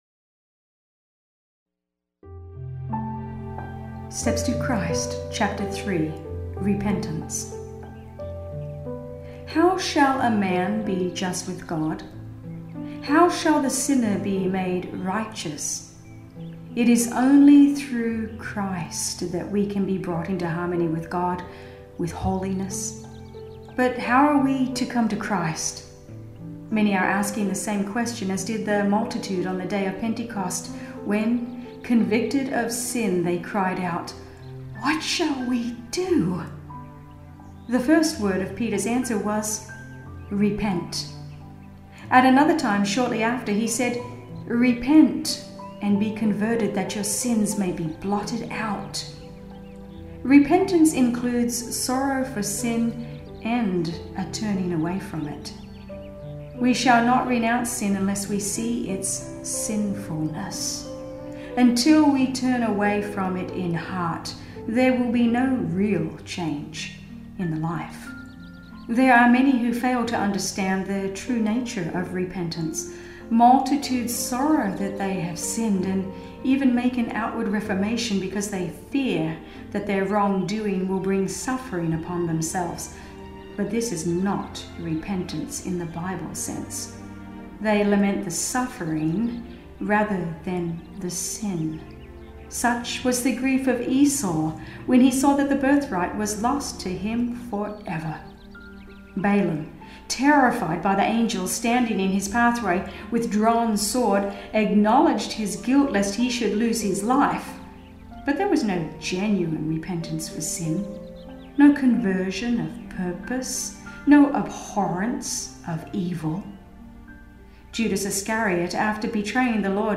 3 - Repentance - Dramatized Version
Steps-to-Christ-Chapter-3-Myers-Media-With-Music-Score-and-Sound-Effects.mp3